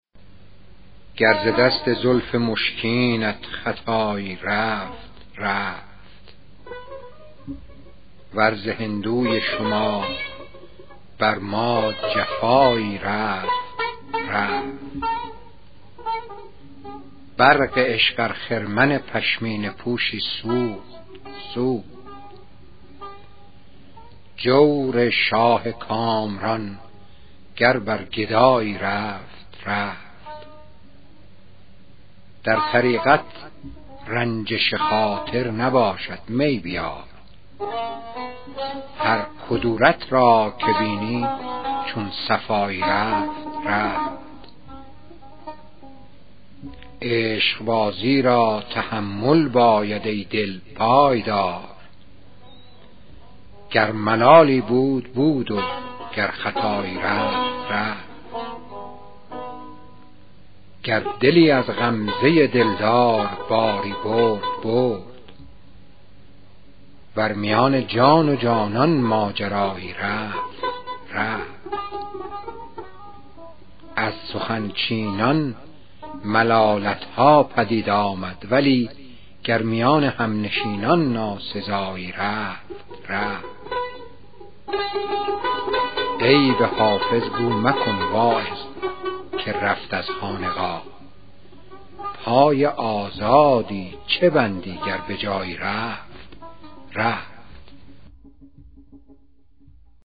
دکلمه غزل شماره 83 دیوان خواجه حافظ شیرازی